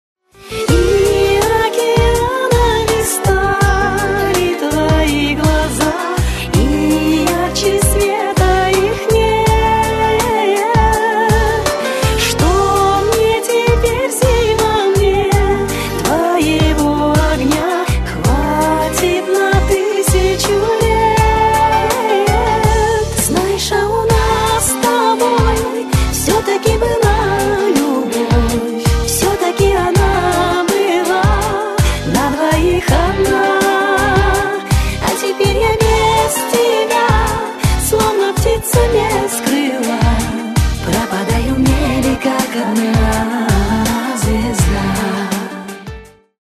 Категория : Шансон (реалтоны)